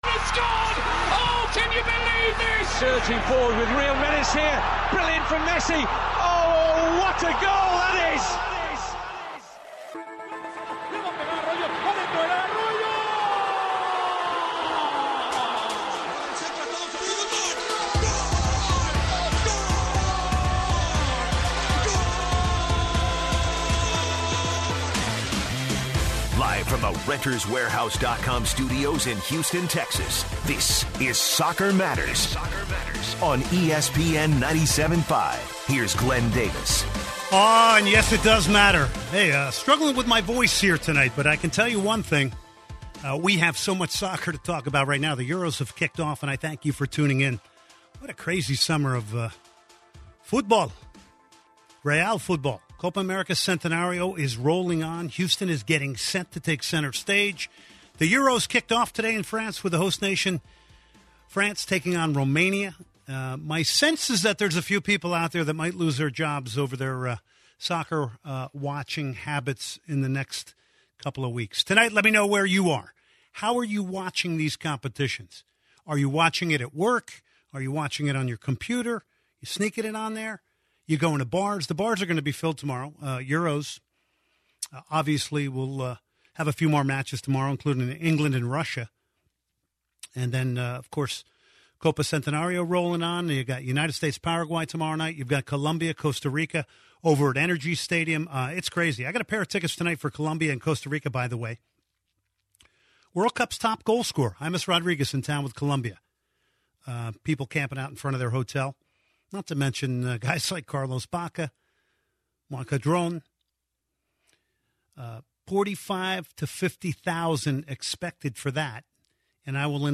Guest speaker Marcelo Balboa former US International talked his predictions on USA and Paraguay game.